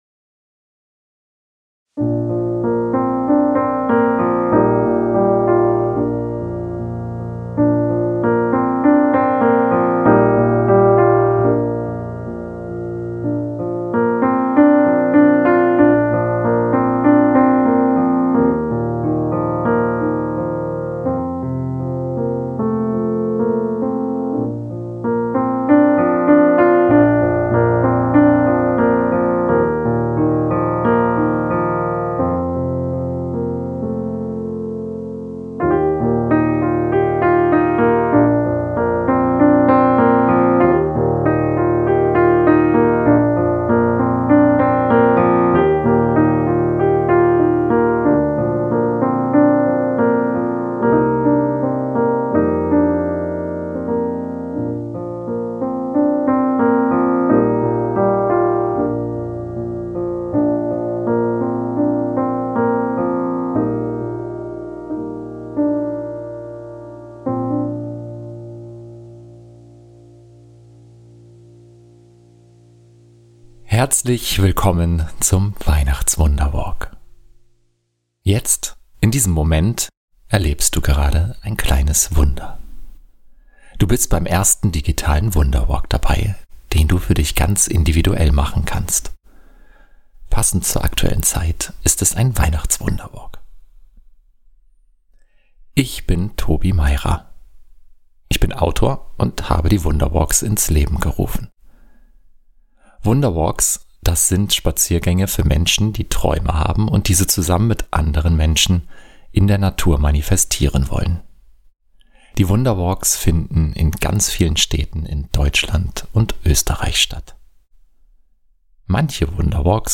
Autor Klavier & Gesang